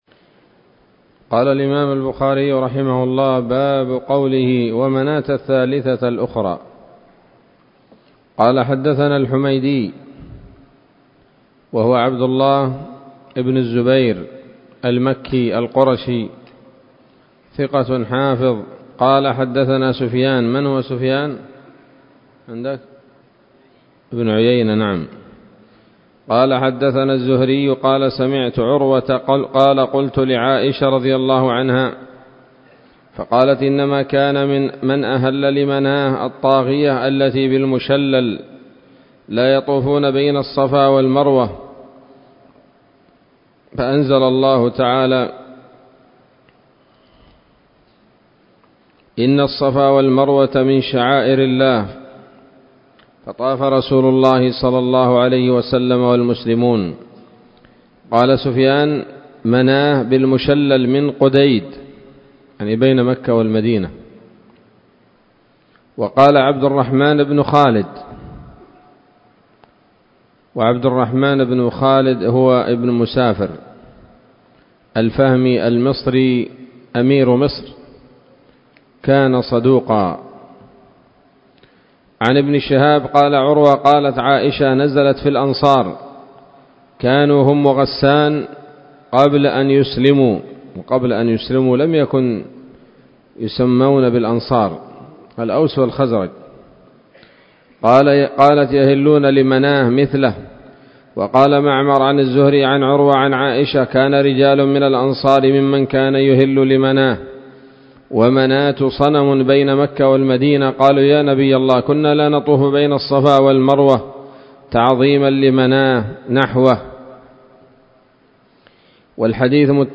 الدرس الرابع والأربعون بعد المائتين من كتاب التفسير من صحيح الإمام البخاري